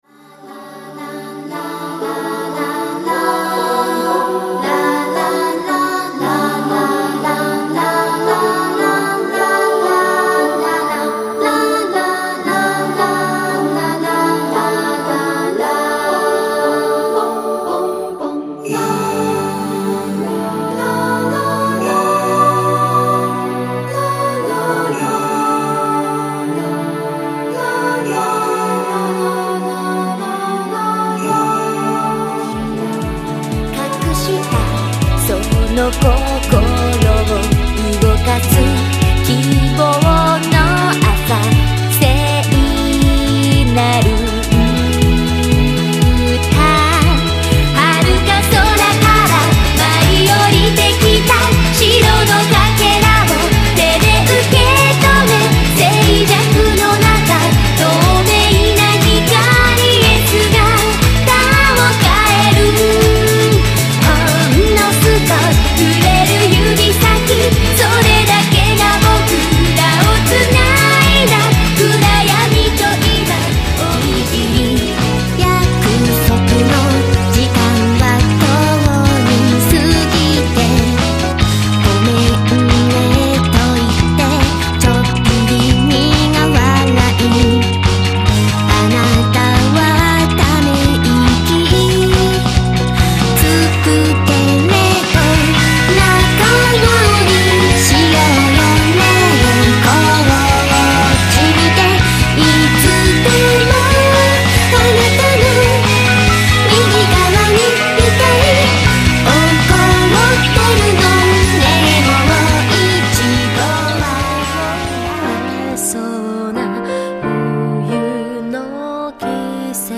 ◎　全曲クロスフェード公開中　⇒
※実際の曲順と同じ順番で収録されています。